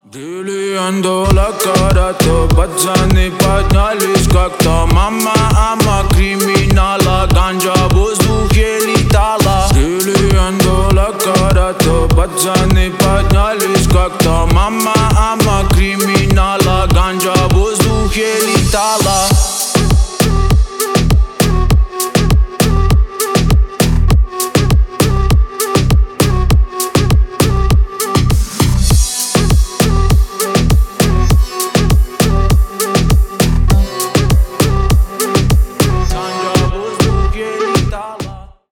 бесплатный рингтон в виде самого яркого фрагмента из песни
Рэп и Хип Хоп
грустные